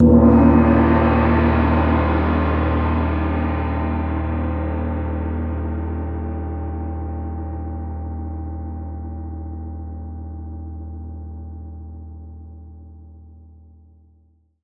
Index of /90_sSampleCDs/Partition E/MIXED GONGS